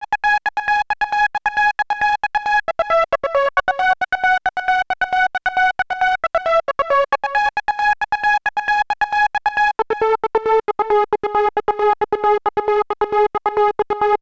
TI CK7 135 Main Arp.wav